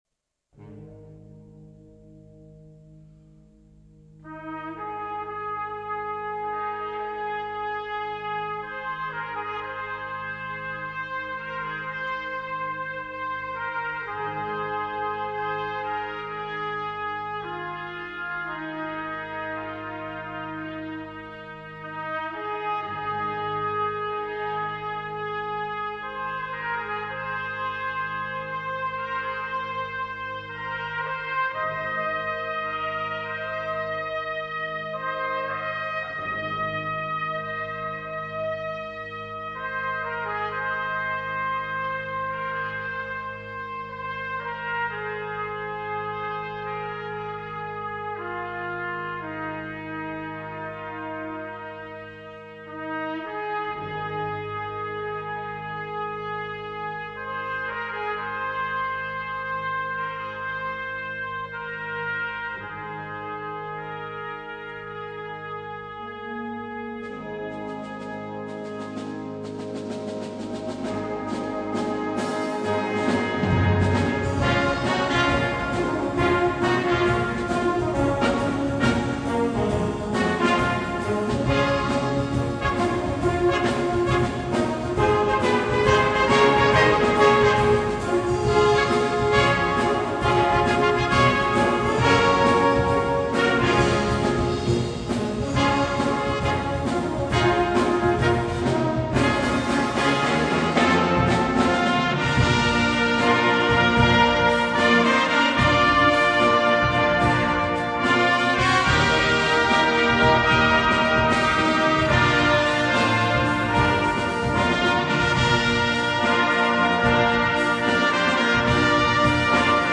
Gattung: Solo für zwei Trompeten und Blasorchester
Besetzung: Blasorchester
Blasorchester, Tambouren und Rhythmusgruppe.
Blues-, Gospel- und Rockelemente